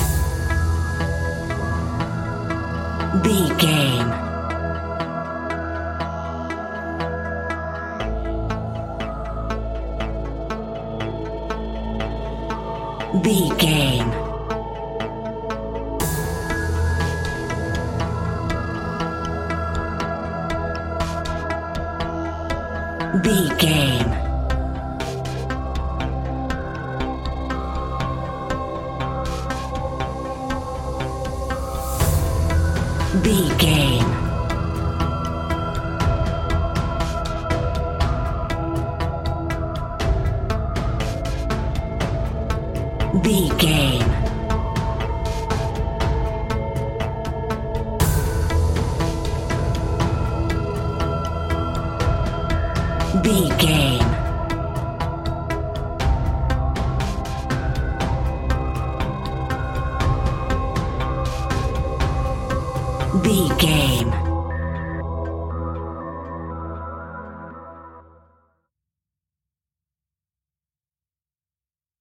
royalty free music
Aeolian/Minor
ominous
dark
haunting
eerie
synthesizer
drum machine
tense
mysterious
ticking
electronic music
Horror Pads
Horror Synths